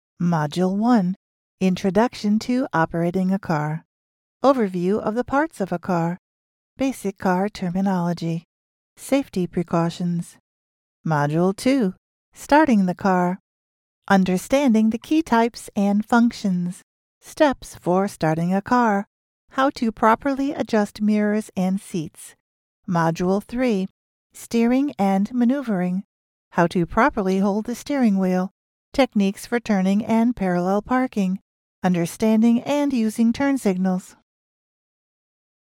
Female
E-Learning
Easy Listening.
Words that describe my voice are Believable, friendly, approachable.